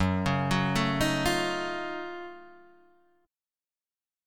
F# Minor Major 13th